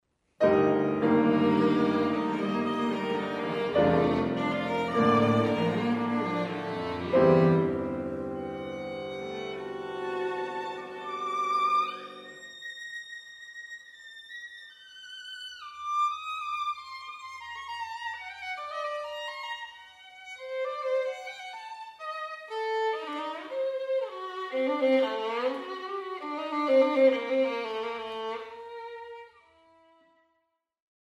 Maestoso
piano
violin
cello